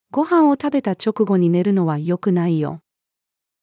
ご飯を食べた直後に寝るのは良くないよ。-female.mp3